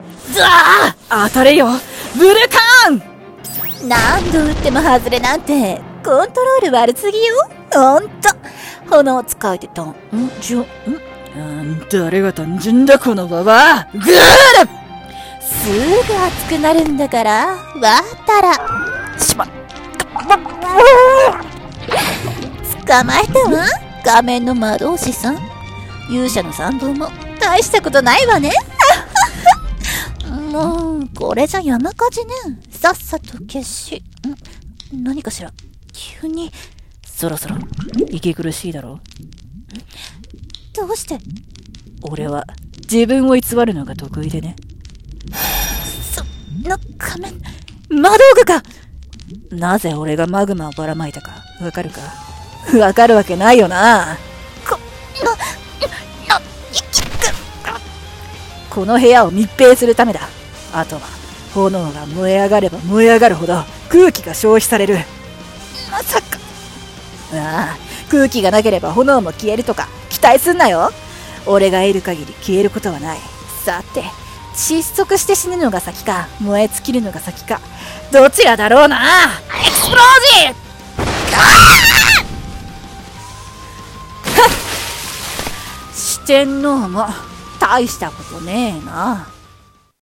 【戦闘系声劇台本】「対決（水と炎）」